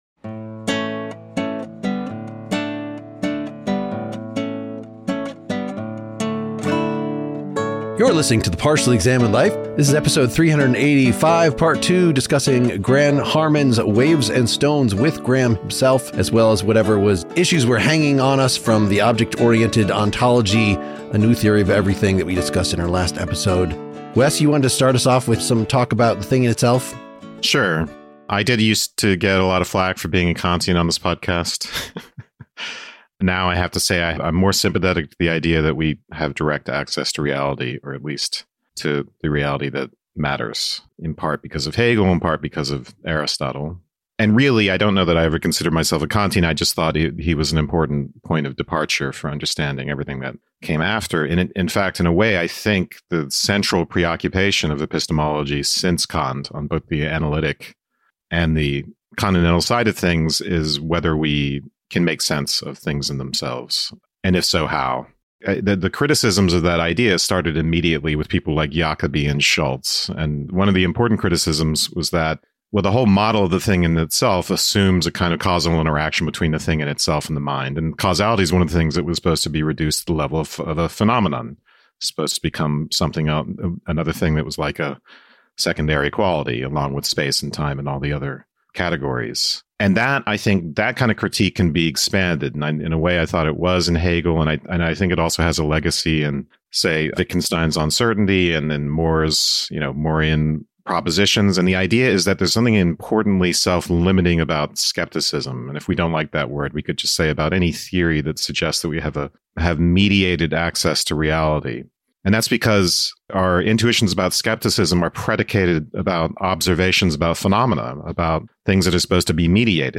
In our continuing Q&A with Graham, we engage him about Kantian Things-In-Themselves, complex things (that if divided, must be cut at the joints) vs. mere heaps, fact ontology, natural kinds, fictional objects, why philosophy is not knowledge, and philosophical style.